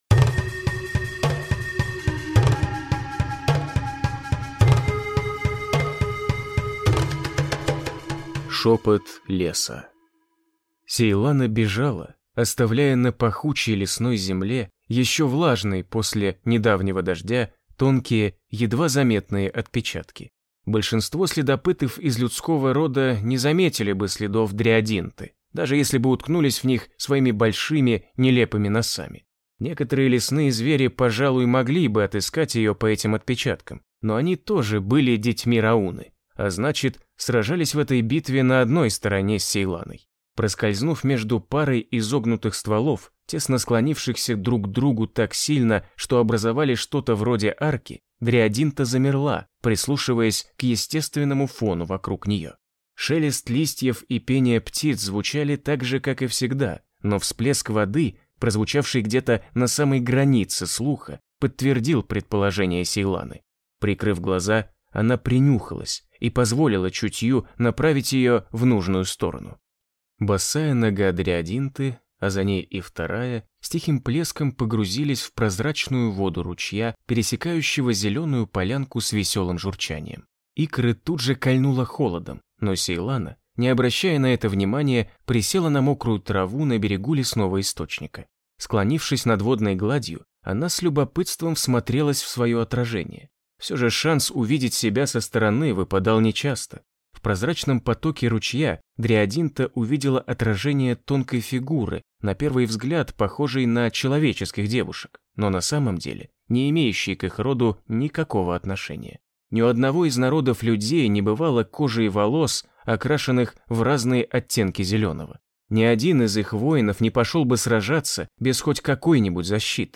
Аудиокнига Tornscape. Шепот Леса | Библиотека аудиокниг